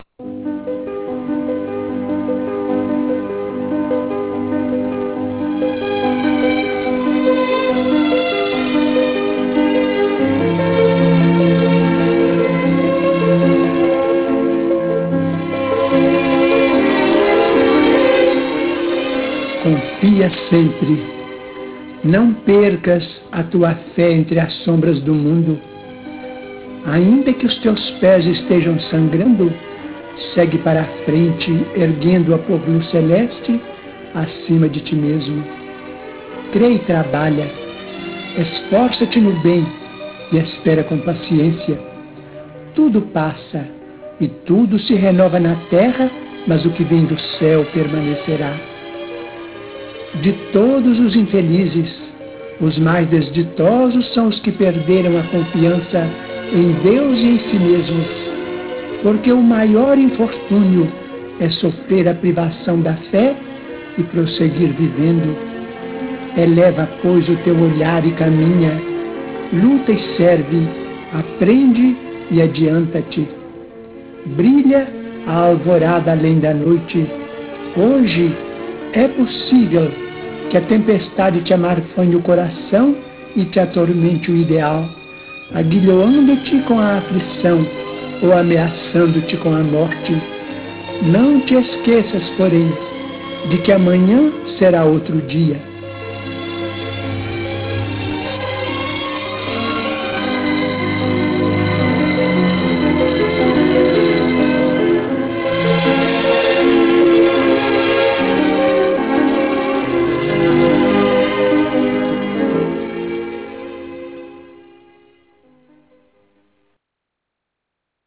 Pra mim, E ISSO É UMA QUESTÃO DE ORDEM PESSOAL, essa mensagenzinha gravada por um homem que já deve estar próximo do fim da belíssima jornada, responde muitas questões: